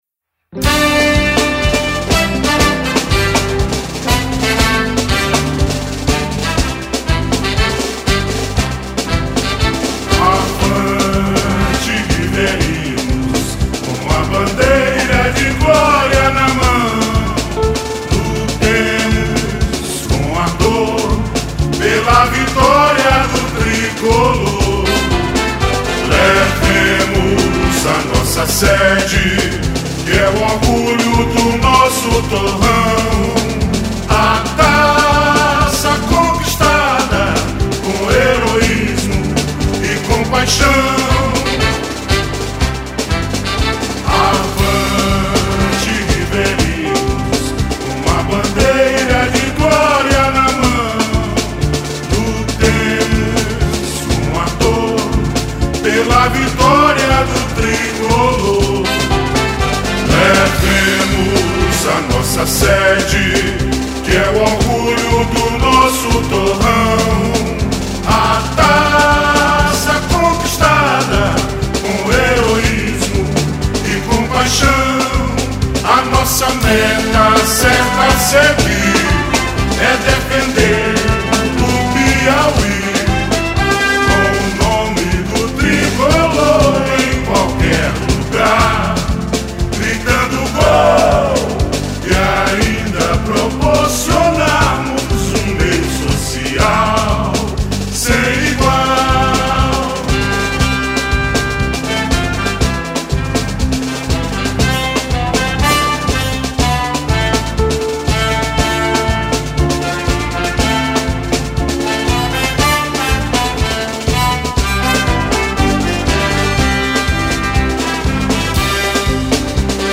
423      Faixa:     Hino